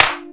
INSTCLAP02-L.wav